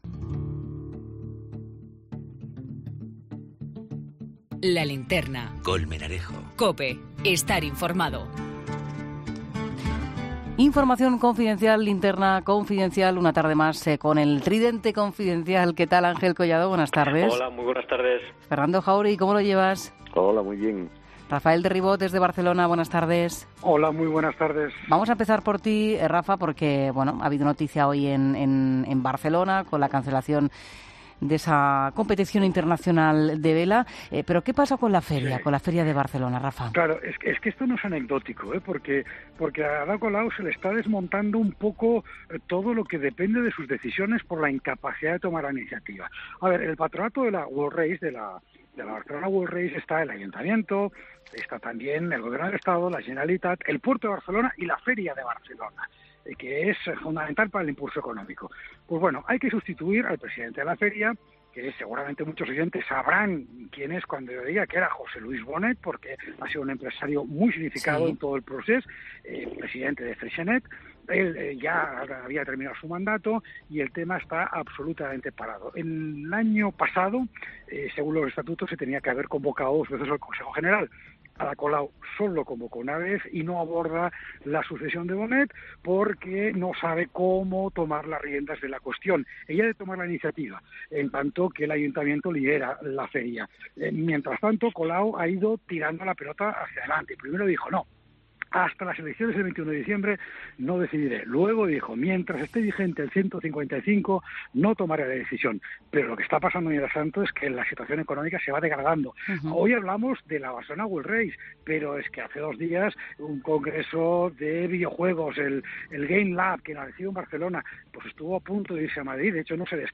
Presentado por Juan Pablo Colmenarejo, uno de los periodistas más prestigiosos de la radio española, el programa es una de las marcas propias de COPE que repasa desde un punto de vista diferente la actualidad política y económica.